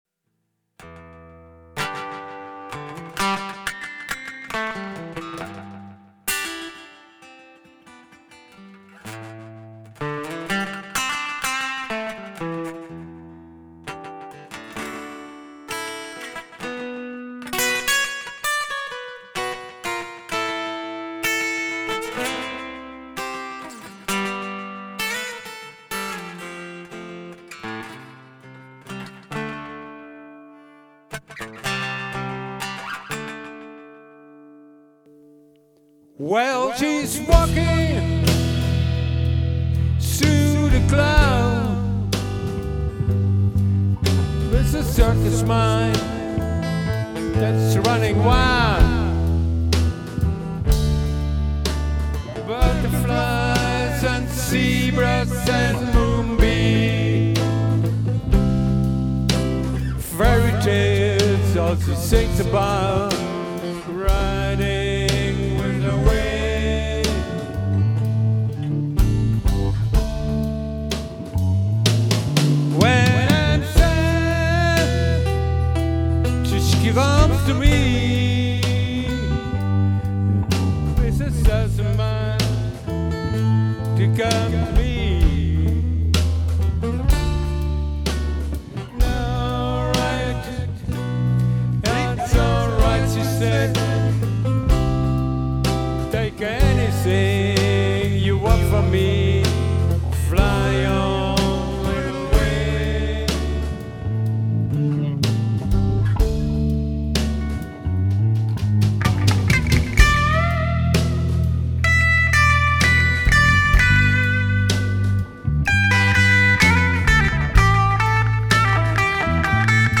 Songs aus unseren Proben platzieren.